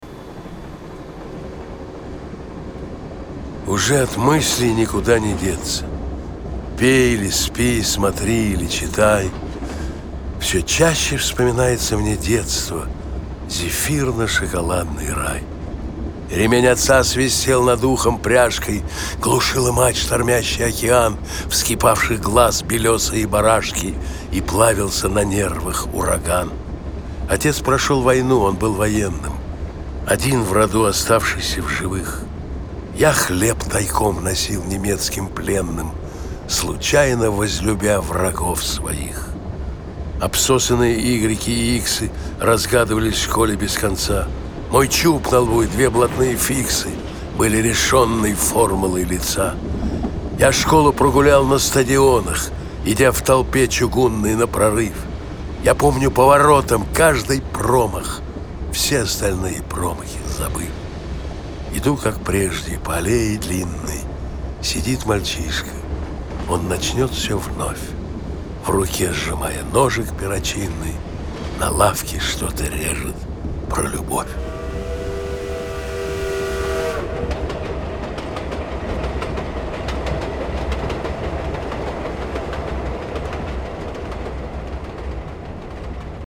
Несколько стихотворений в исполнении автора:
valentin-gaft---uje-ot-myisley-nikuda-ne-detsya-(chitaet-avtor).mp3